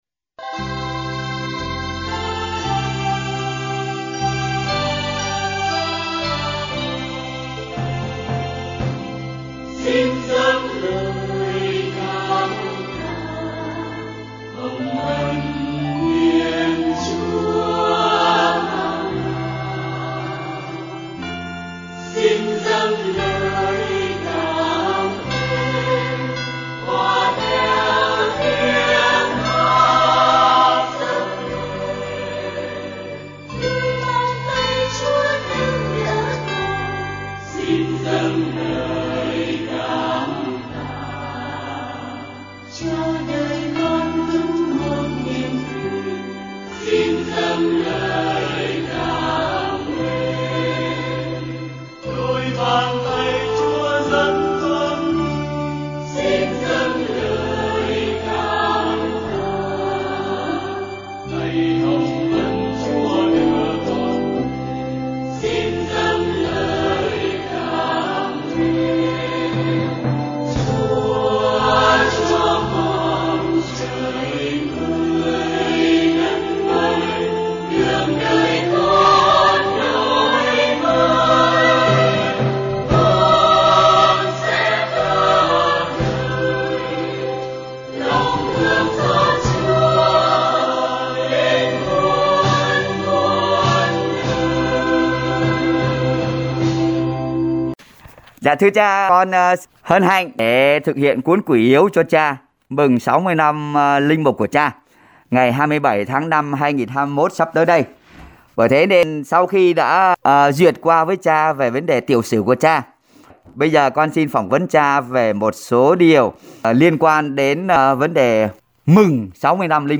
cuộc phỏng vấn